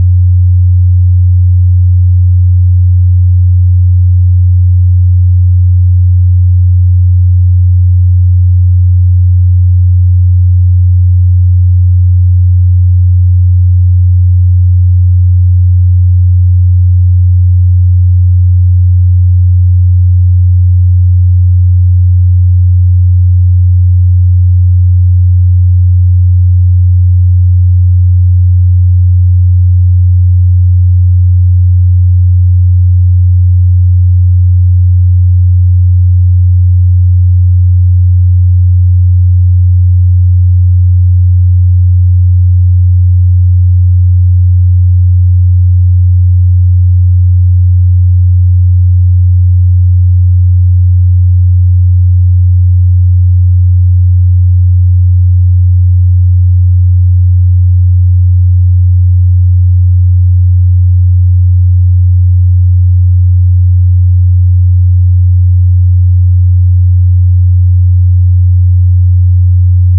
90 Hz Sinuston, Länge 70 Sekunden
90-Hz-Test.mp3